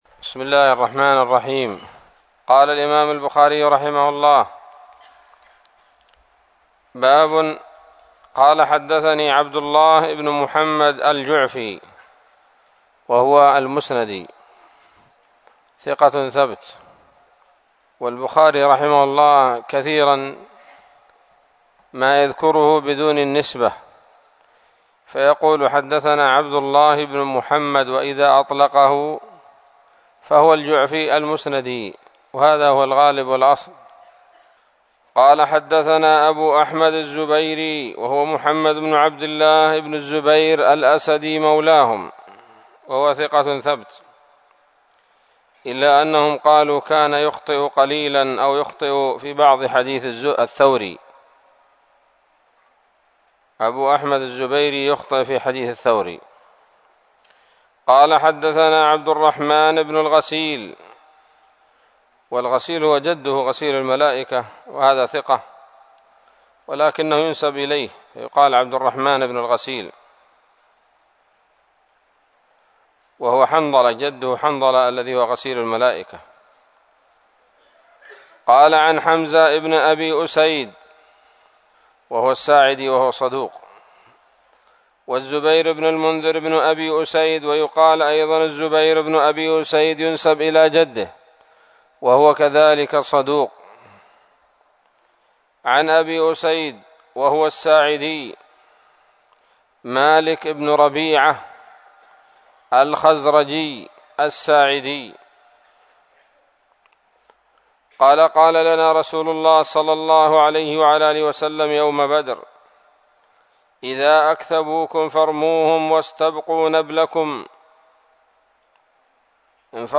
الدرس الثالث عشر من كتاب المغازي من صحيح الإمام البخاري